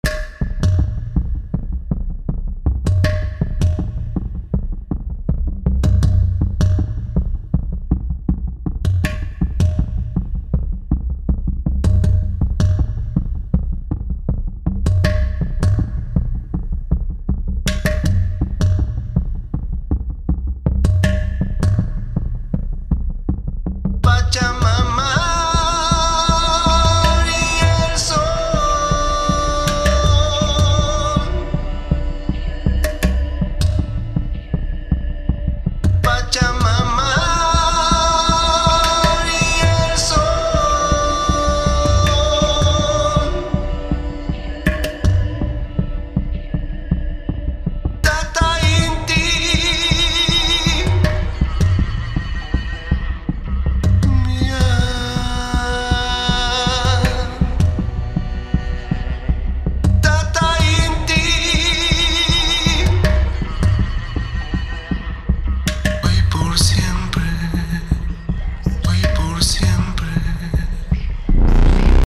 Interne Effekte der Sample-Instrumente habe ich weitgehend ausgeschaltet – mit Ausnahme von Chorus, Echo und Kompressor bei Omnisphere, da diese maßgeblich am Sound beteiligt sind.
Es ist also genau genommen so, als ob die Instrumente alle in verschiedenen Räumen spielen würden.
Da mir der Gesang ein wenig zu sauber und Hi-Fi-mässig rüberkommt, verpasse ich ihm noch die Mikrofonsimulation „The King´s Microphones“ von Abbey Road/Waves – die, wie ich finde, beste Mikrofonsimulation für Vocals.
Außerdem hätte ich gerne einen schrägen Chor.
Octavox bietet temposynchrone Pitch-Shift-Delays mit Skalenkorrektur. Unser Freund aus Peru singt in E-Moll – zumindest überwiegend.
Bis Takt 24 hört es sich nun so an: